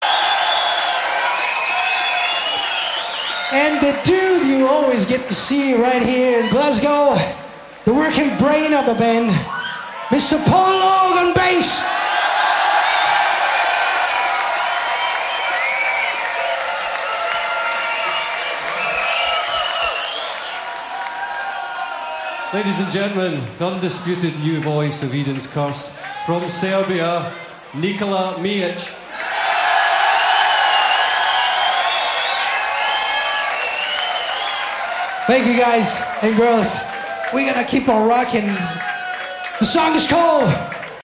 Band Introductions